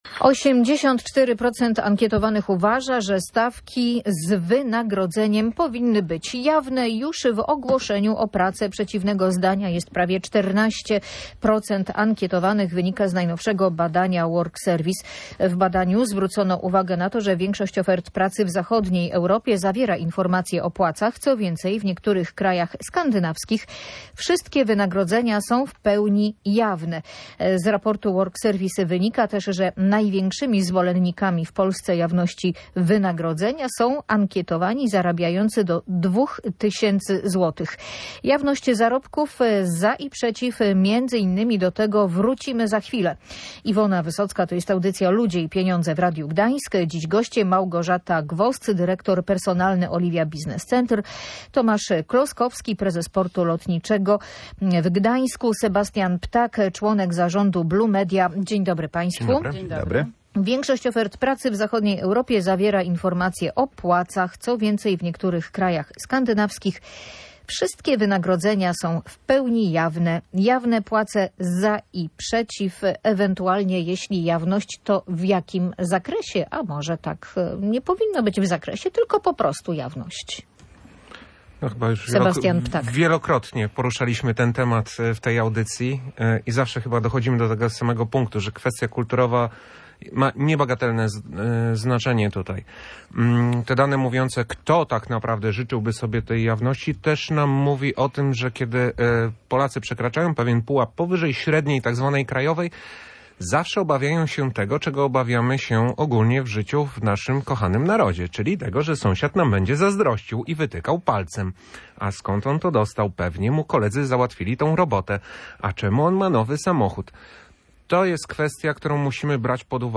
O plusach i minusach tych rozwiązań rozmawiali eksperci w audycji Ludzie i Pieniądze.